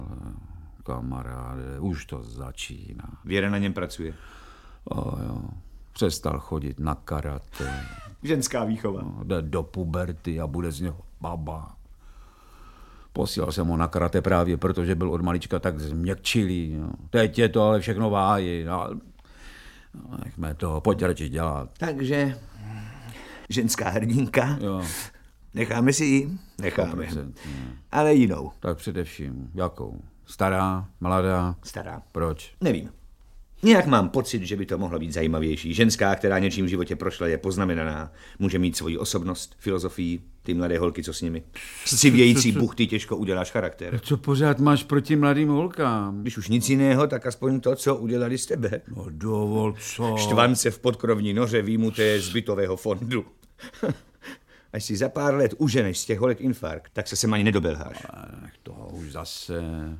Audiobook
Audiobooks » Short Stories
Read: Vladimír Brabec